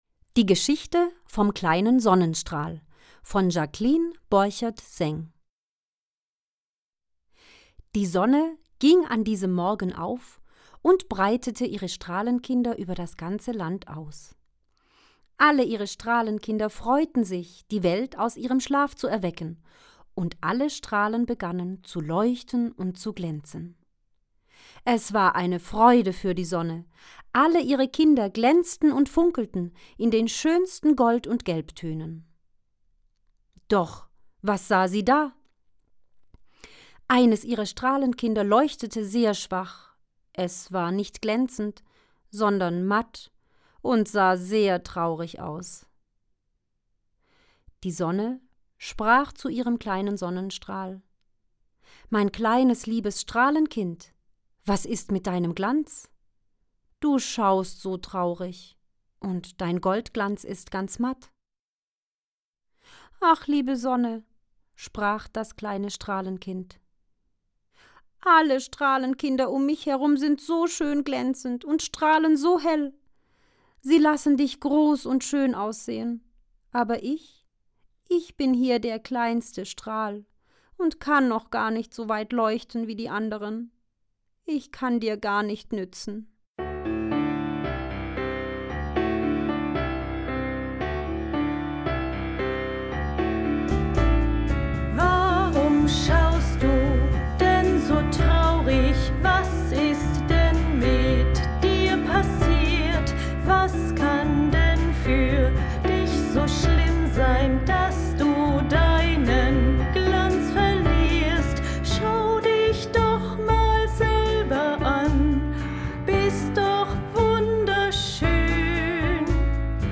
Hörbuch, Lesebuch & Kinderbuch
sonnenstrahl_hoerbuch.mp3